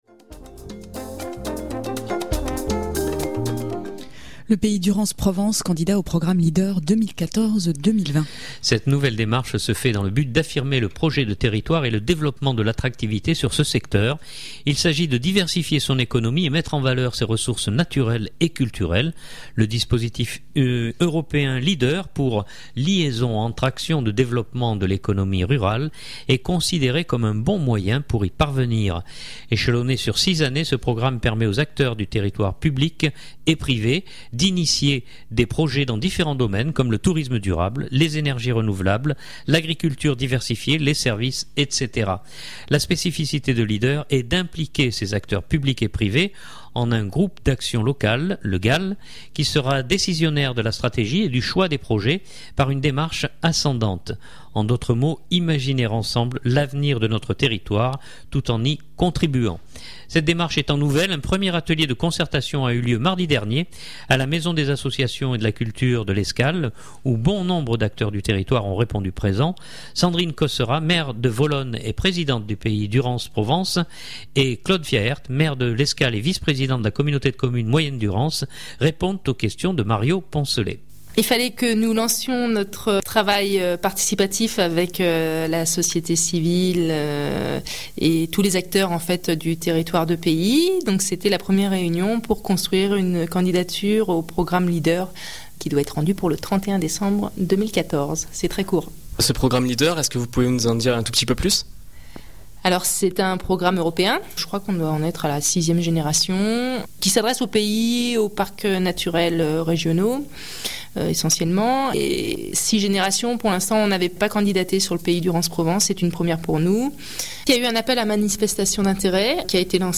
Cette démarche étant nouvelle, un premier atelier de concertation a eu lieu mardi dernier à la Maison des Associations et de la Culture de L’Escale, où bon nombre d’acteurs du territoire ont répondu présents. Sandrine Cosserat, Maire de Volonne et Présidente du Pays Durance Provence et Claude Fiaert, Maire de l’Escale, Vice-président de la Communauté de Communes Moyenne Durance et Conseiller Général donnent quelques détails supplémantaires.